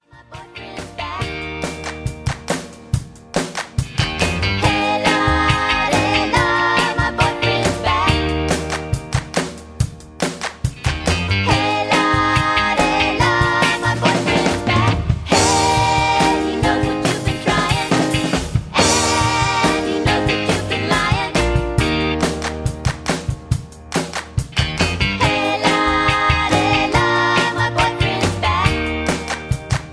(Key-Eb) Karaoke Mp3 Backing Tracks